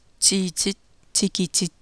9.1: gr�nlandsk [... ti:tit ... tikitit]